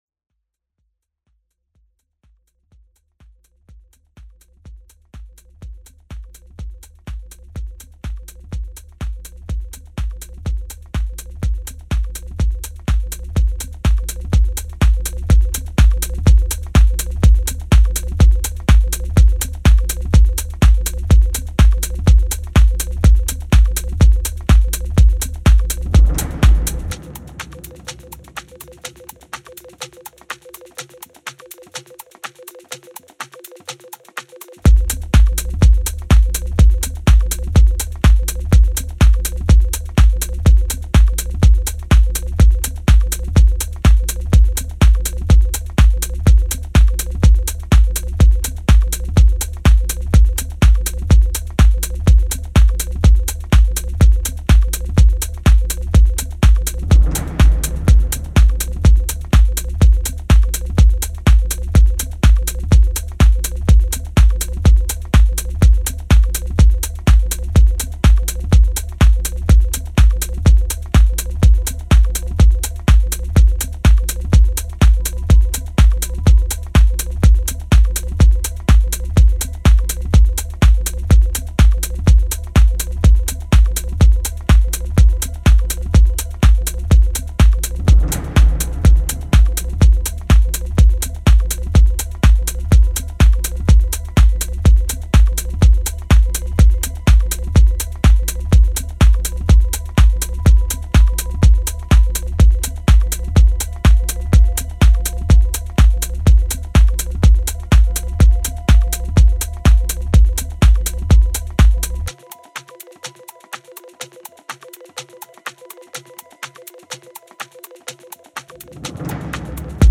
Four Groovy, Glittering Tracks
Style: Techno / Minimal